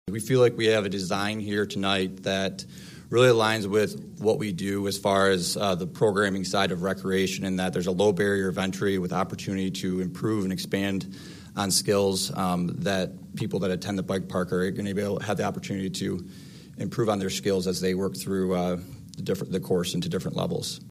The Holland City Council’s business meeting was in Chambers at Holland City Hall.